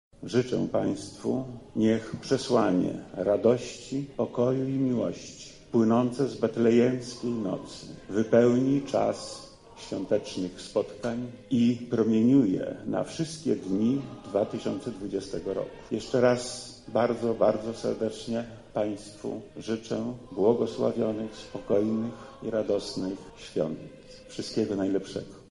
Opłatkiem podzielili się dzisiaj pracownicy Urzędu Wojewódzkiego.
Oficjalne życzenia wszystkim mieszkańcom naszego województwa złożył wojewoda Lech Sprawka: